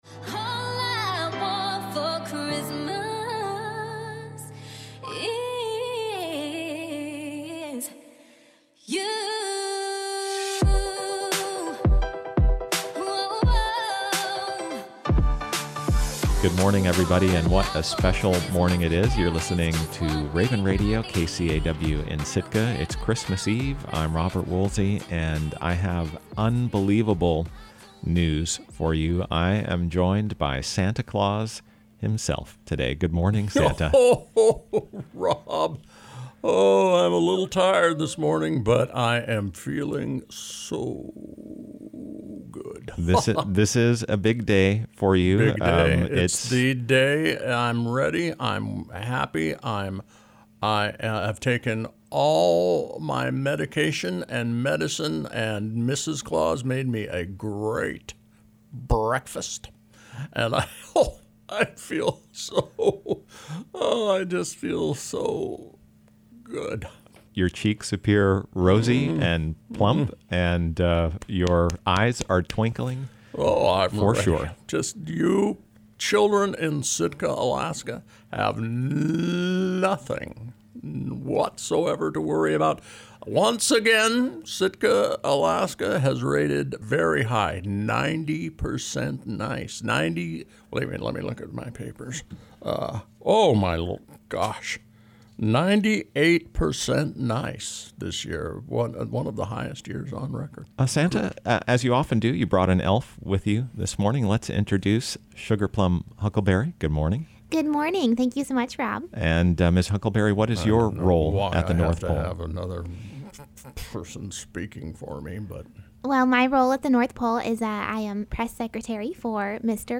A conversation with the Jolly Northern Elf suddenly goes south when a former business associate, Mumsy Krinklenose, implicates Santa in "Wish Graft" and other high crimes. Only a steady diet of lemon drops -- and firm denials by press secretary Sugar Plum Huckleberry -- can hold Santa together, and keep Christmas on track.